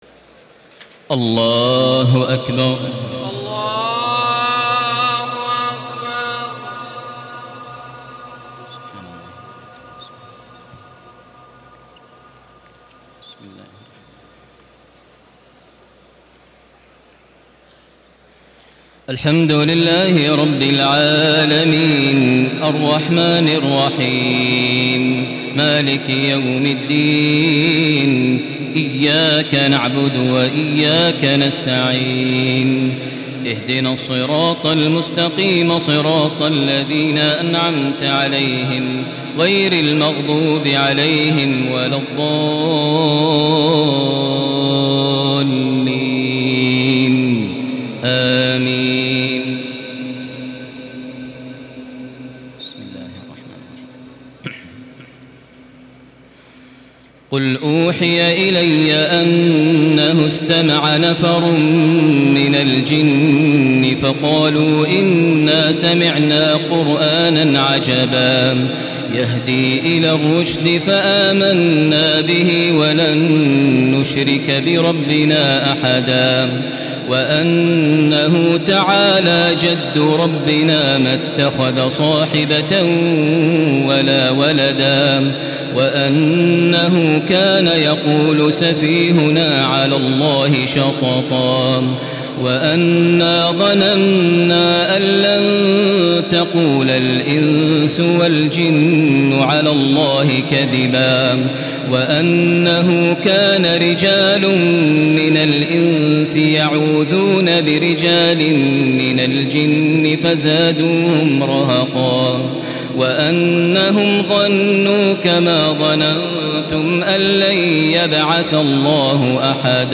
تراويح ليلة 28 رمضان 1435هـ من سورة الجن الى المرسلات Taraweeh 28 st night Ramadan 1435H from Surah Al-Jinn to Al-Mursalaat > تراويح الحرم المكي عام 1435 🕋 > التراويح - تلاوات الحرمين